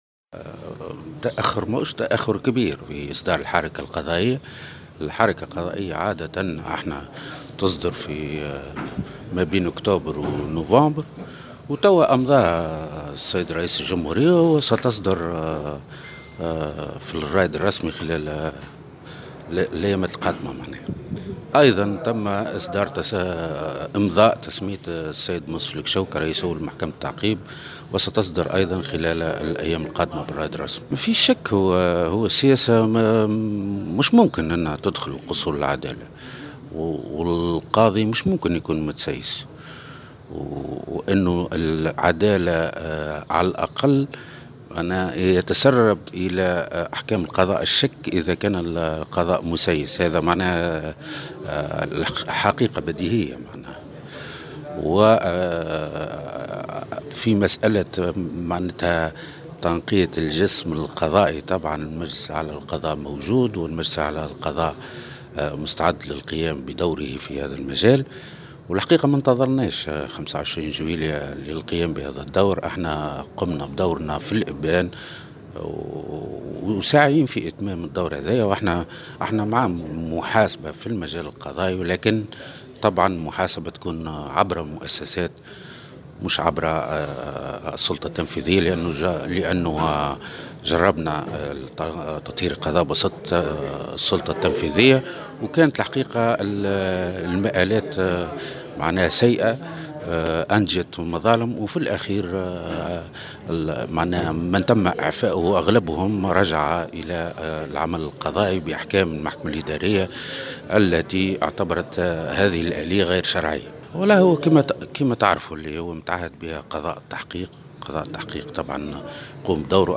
وأفاد بوزاخر، في تصريح لمراسلة الجوهرة اف أم، اليوم السبت، بأنه قد تمت تسمية منصف الكشو رئيسا لمحكمة التعقيب وسيقع إصدارها كذلك بالرائد الرسمي.